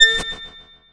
贡献 ） 分类:游戏音效 您不可以覆盖此文件。